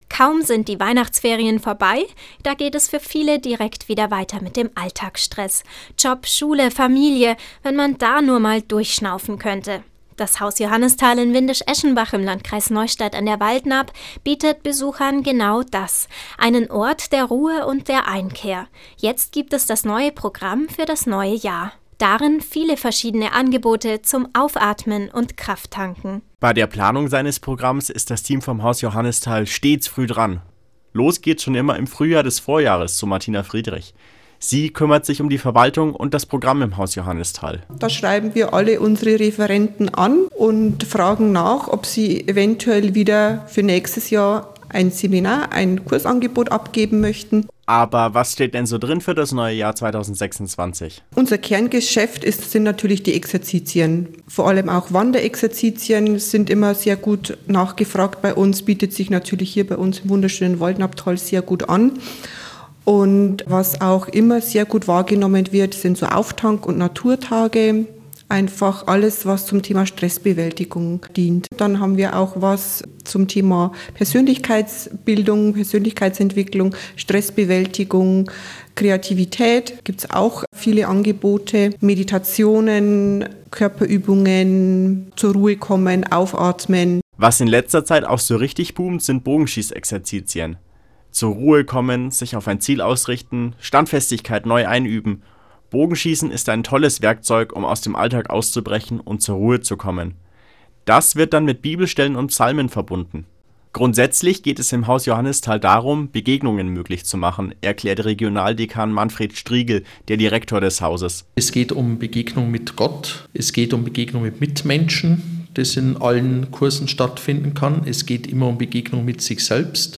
Radio-Interview